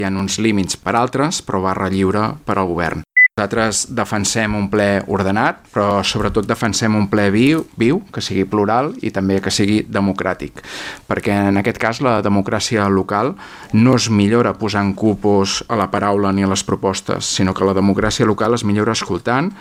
Recuperem les seves intervencions durant la sessió d’abril:
Xavier Ponsdomènech, portaveu ERC: